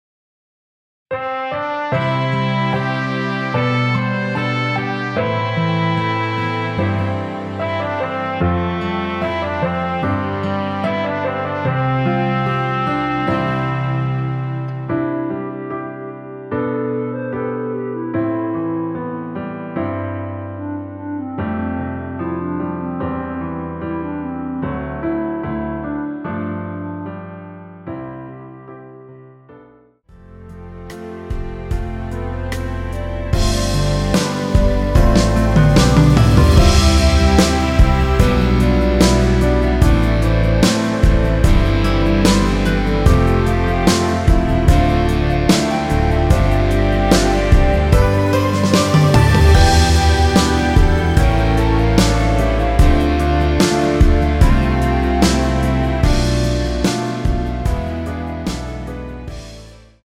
원키 멜로디 포함된 MR입니다.(미리듣기 확인)
멜로디 MR이라고 합니다.
앞부분30초, 뒷부분30초씩 편집해서 올려 드리고 있습니다.